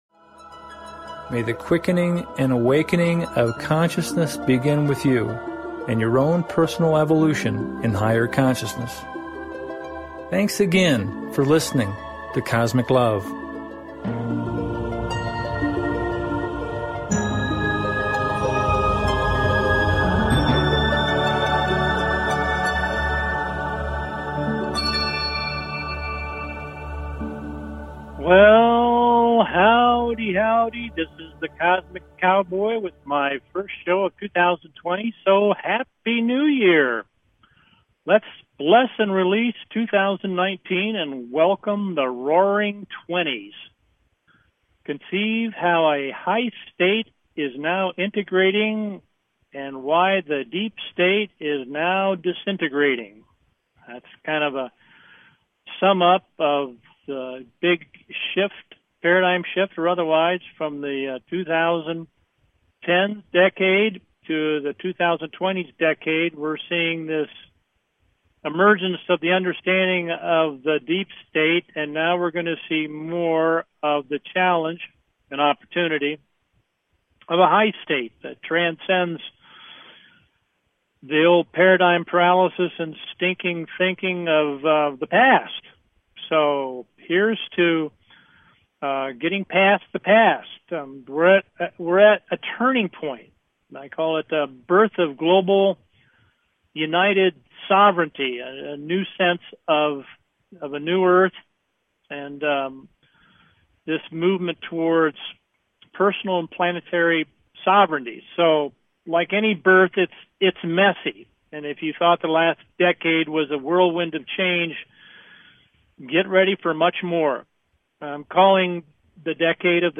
Cosmic LOVE Talk Show